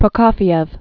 (prə-kôfē-ĕf, -əf, -kō-, -kôfyĭf), Sergei Sergeyevich 1891-1953.